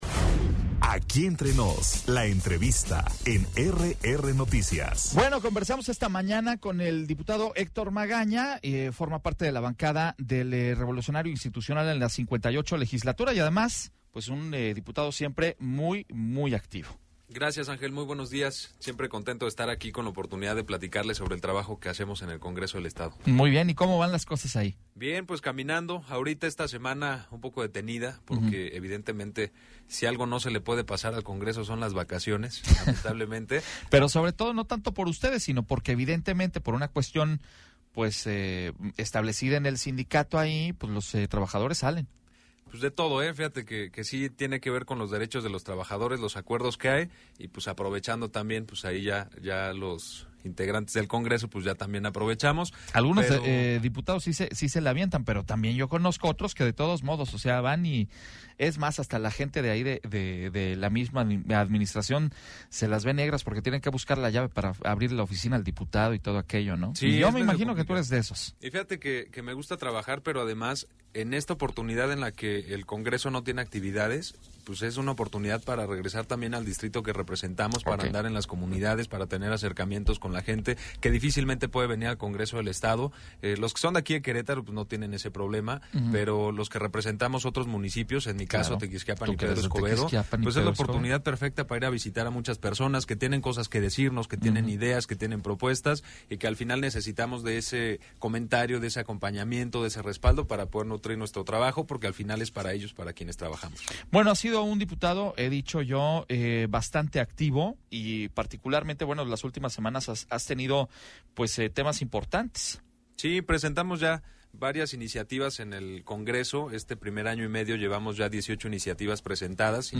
EntrevistasMultimediaPodcast
Entrevista con el presidente de la comisión de Puntos Constitucionales del Congreso Local, Héctor Magaña